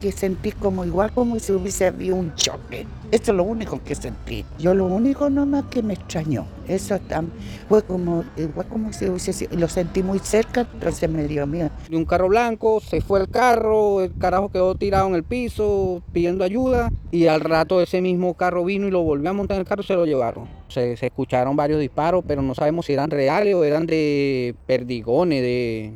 cuna-mix-testigos.mp3